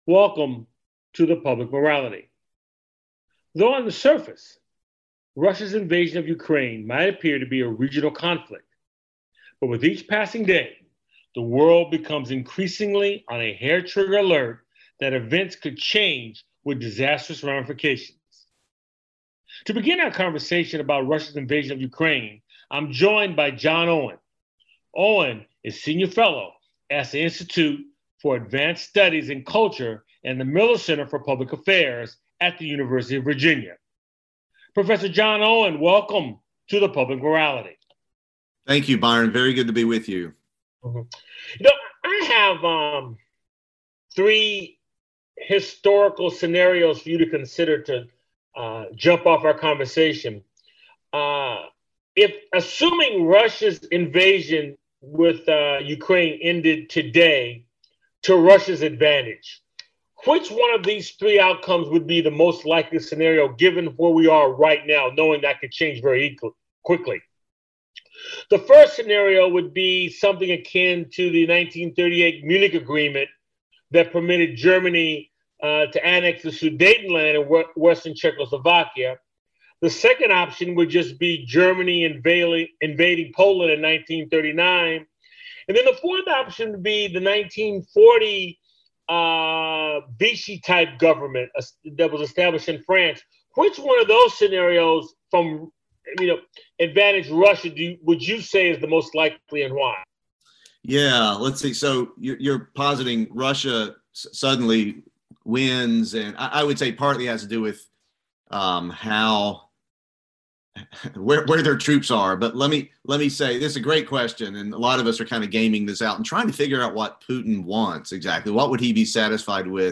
It's a weekly conversation with guest scholars, artists, activists, scientists, philosophers and newsmakers who focus on the Declaration of Independence, the Constitution and the Emancipation Proclamation as its backdrop for dialogue on issues important to our lives. The show airs on 90.5FM WSNC and through our Website streaming Tuesdays at 7:00p.